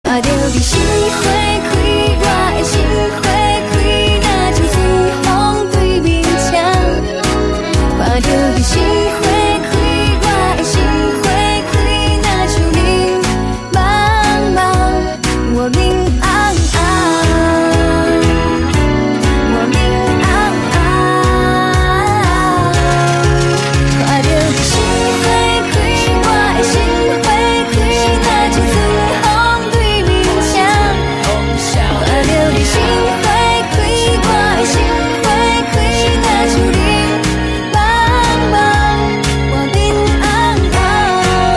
M4R铃声, MP3铃声, 华语歌曲 99 首发日期：2018-05-15 21:42 星期二